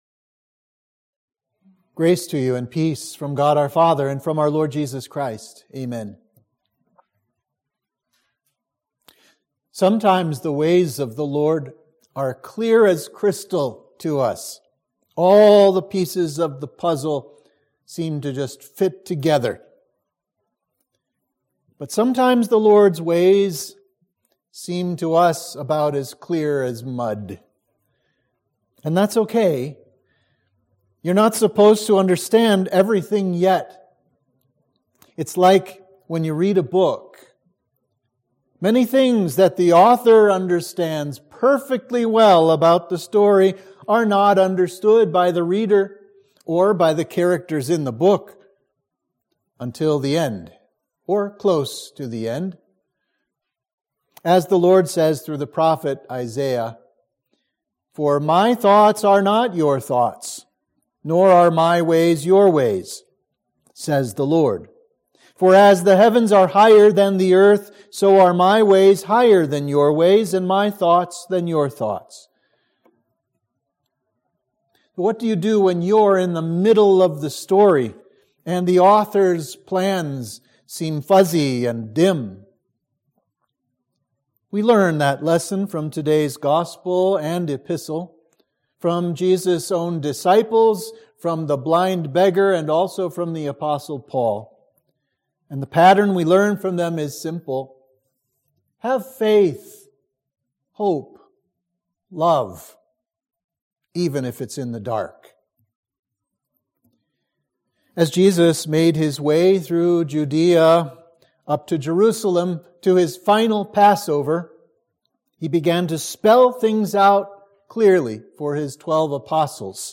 Sermon for Quinquagesima